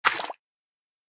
دانلود آهنگ رودخانه 1 از افکت صوتی طبیعت و محیط
دانلود صدای رودخانه 1 از ساعد نیوز با لینک مستقیم و کیفیت بالا
جلوه های صوتی